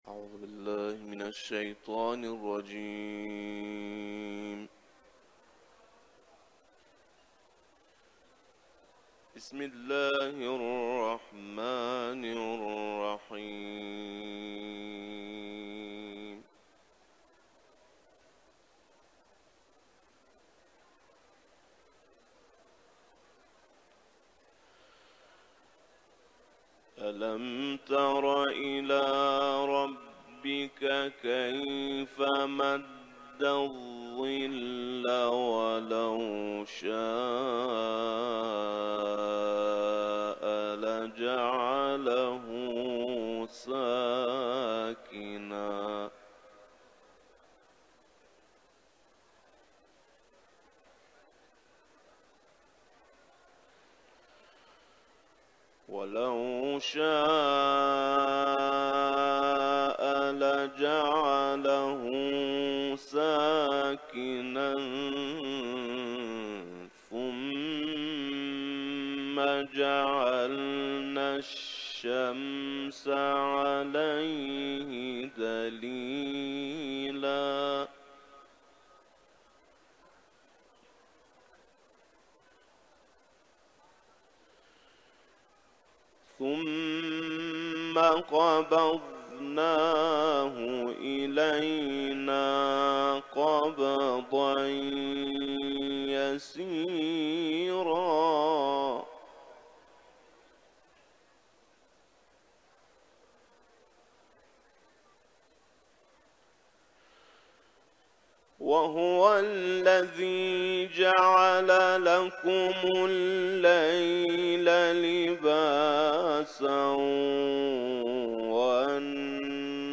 Uluslararası kâri Furkan suresinin 45-57 ayetlerini tilavet etti:
Etiketler: kuran ، tilavet ، Furkan suresi ، İranlı kâri